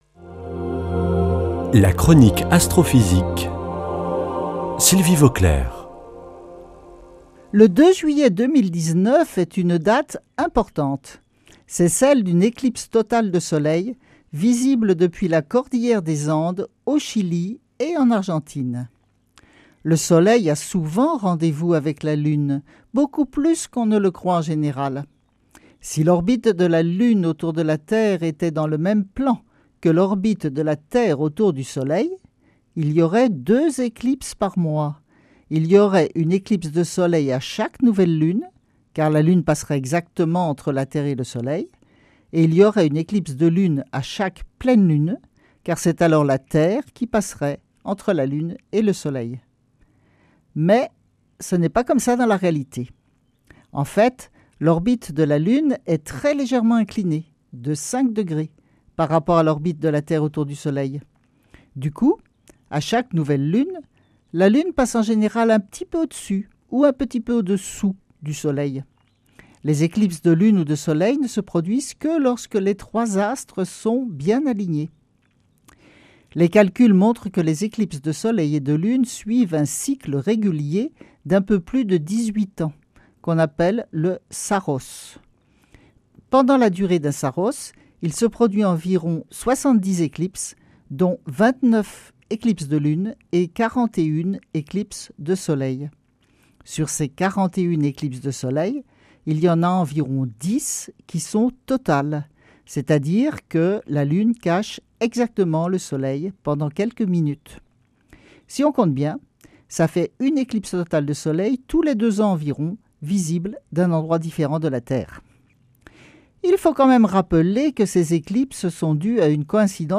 Sylvie Vauclair
Astrophysicienne